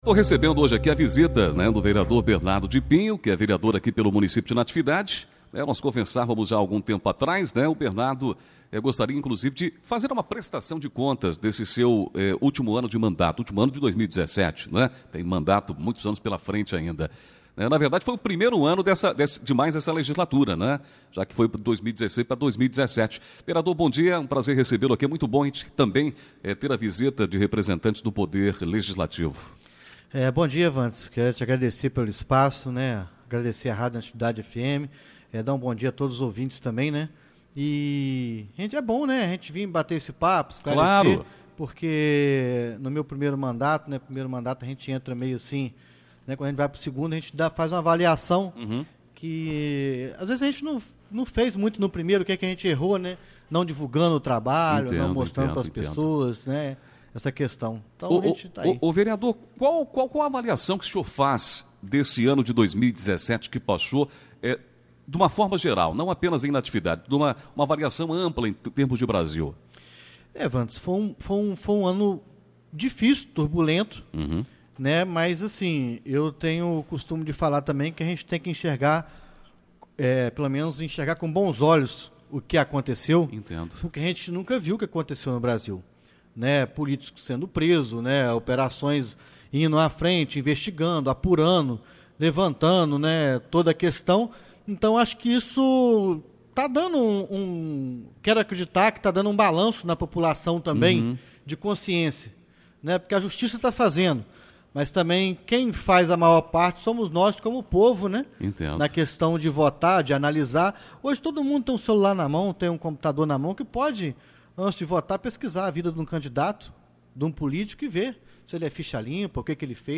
3 janeiro, 2018 ENTREVISTAS, NATIVIDADE AGORA
ENTREVISTA-BERNARDO.mp3